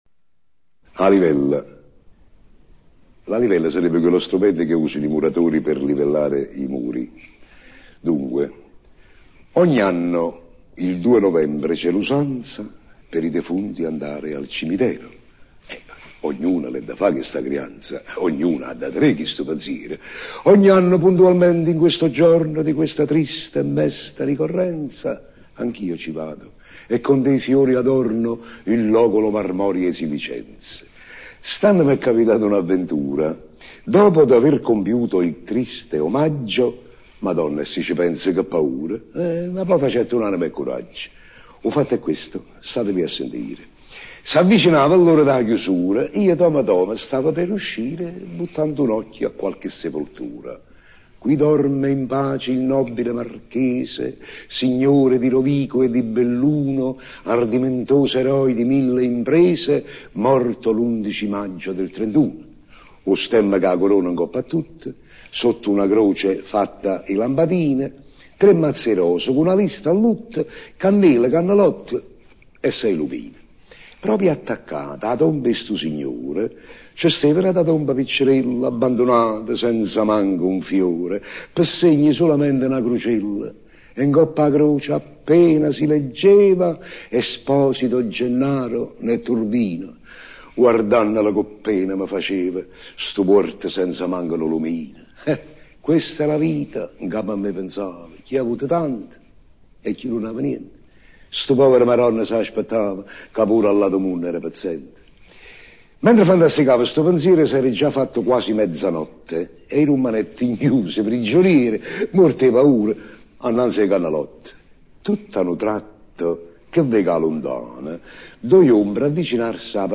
- "A LIVELLA" Poesia declamata dal Principe De Curtis - Tot�